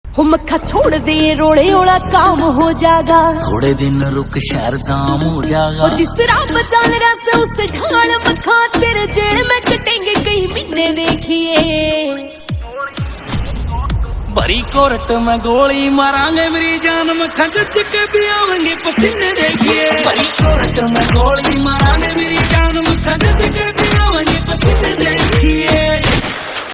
new haryanvi song